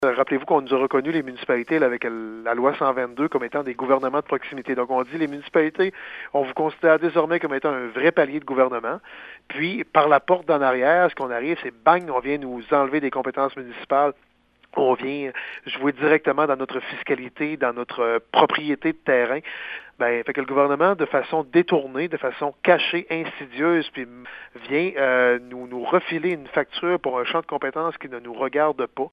Dans un premier temps, le maire Daniel Côté critique la façon de faire de Québec, qui en catimini, est venu enlever des pouvoirs aux municipalités: